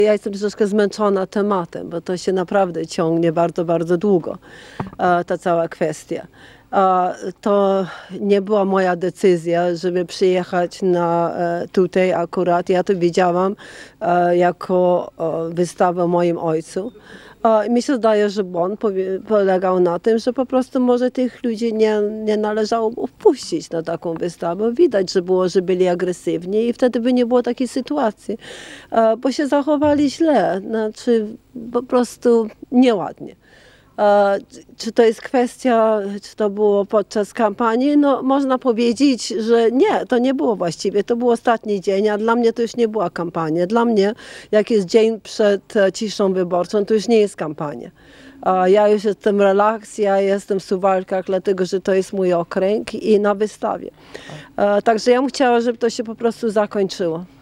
W piątek (17.08) przy okazji wizyty polonijnej młodzieży z Wielkiej Brytanii w wigierskim klasztorze, Anna Maria Anders udzieliła krótkiego wywiadu Radiu 5, w którym odniosła się do stawianych jej zarzutów.